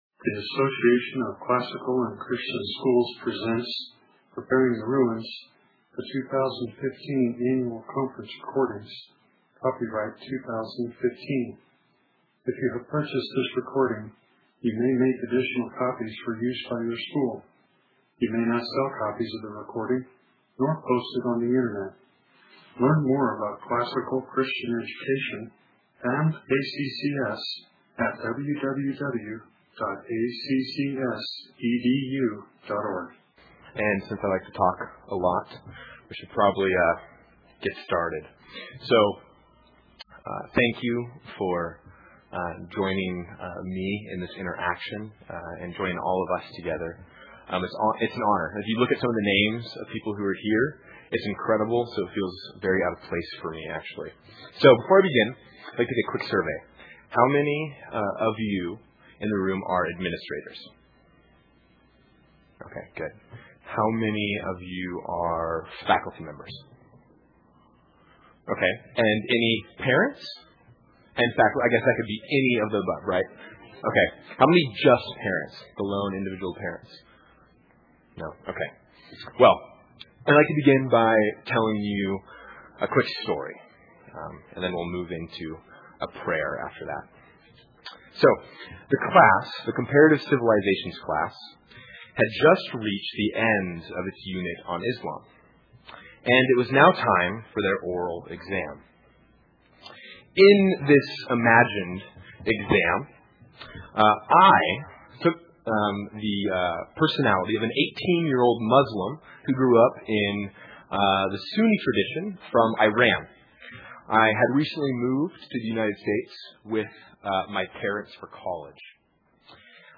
2015 Practicum Talk, 1:15:03, 7-12, General Classroom